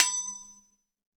bell ding percussion spackle-knife sound effect free sound royalty free Sound Effects